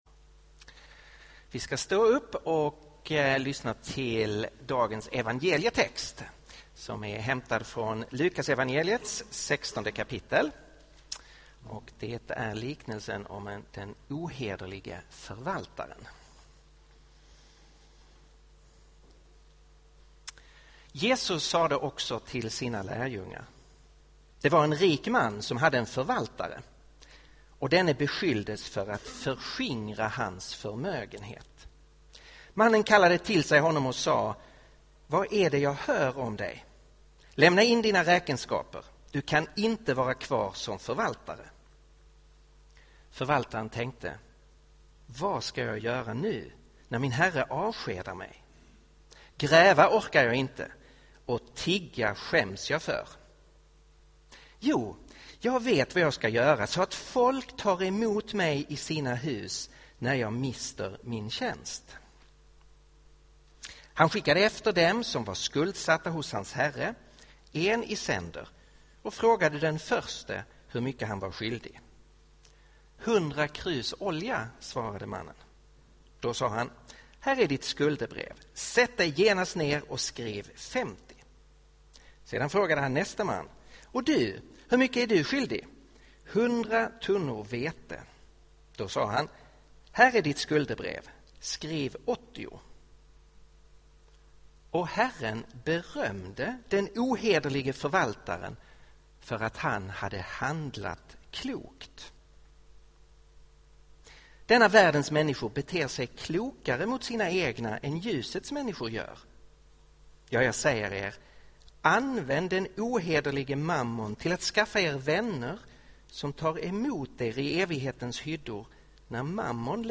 Inspelad vid Betlehemskyrkan i Stockholm 2014-08-17.